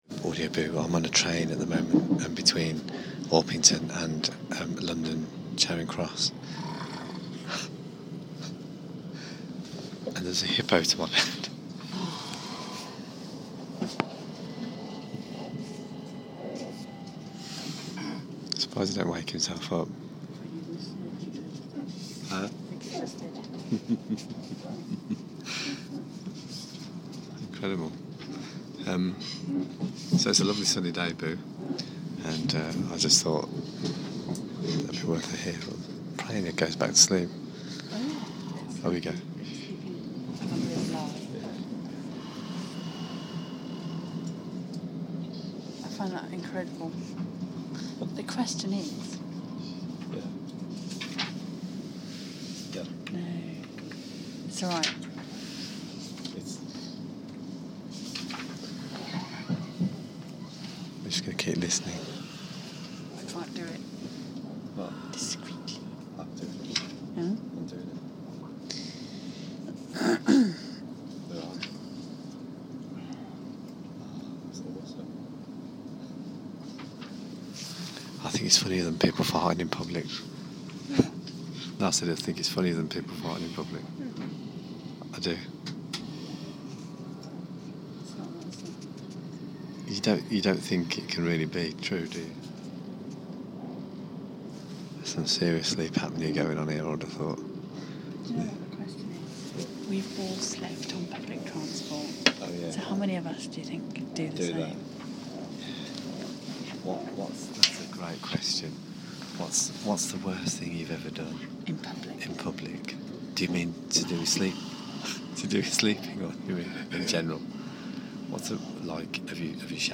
To the backdrop of what can only be described as the loudest snorer i have ever heard